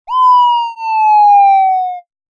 drop.ogg.mp3